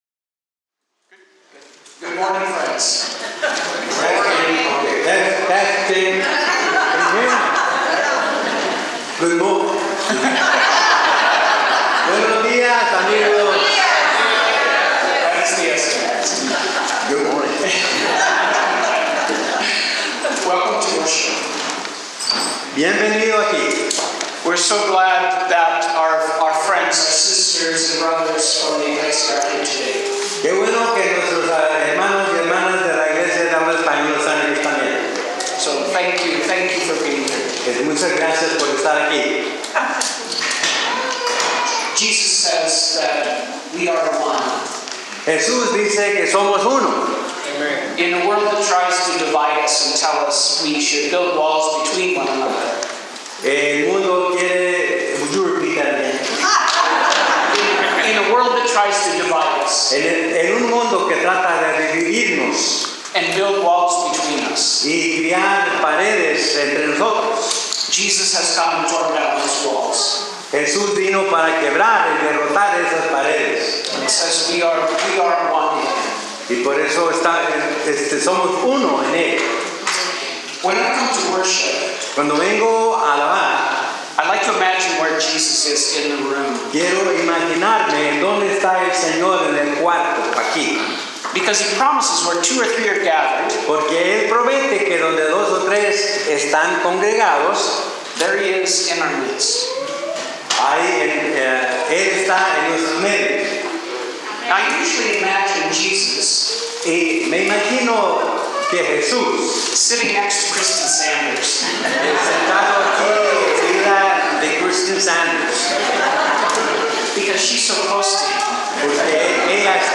On the first Sunday of Advent, NVFC was joined by La Iglesia Los Amigos de Newberg.